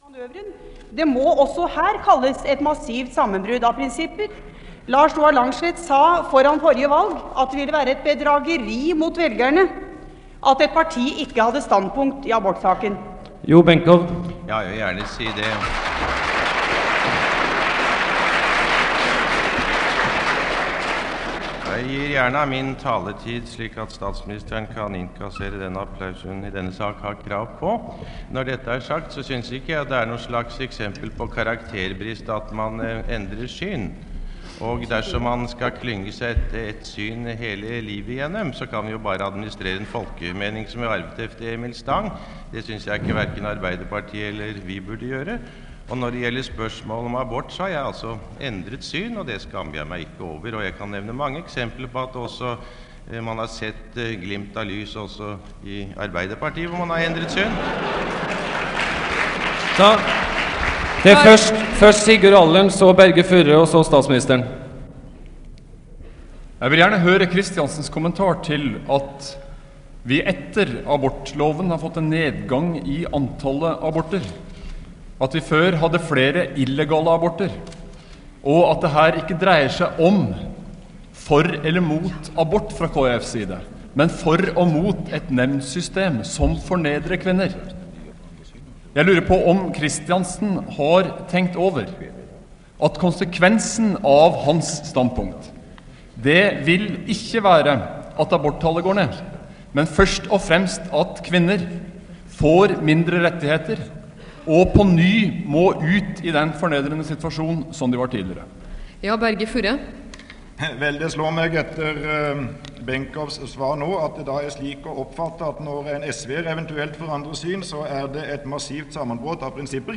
Det Norske Studentersamfund, Lydbånd og foto, Foredrag, debatter, møter, nr. 74.1: Partilederdebatt, 4.9.1981